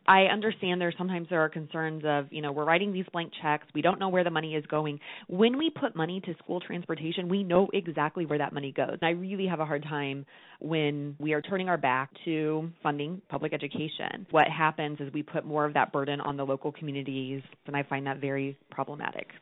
Democratic Representative Maggie Nurrenbern (NUR-ren-burn), of Clay County, says she wants to increase funding for school transportation in the coming year. She spoke with Missouri News Network earlier this morning before the House convened.